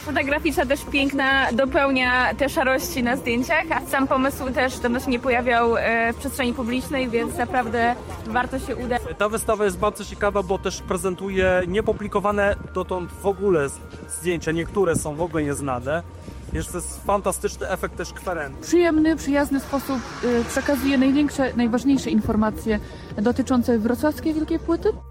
A co na temat samej wystawy sądzi reszta mieszkańców Wrocławia? Posłuchajcie sami: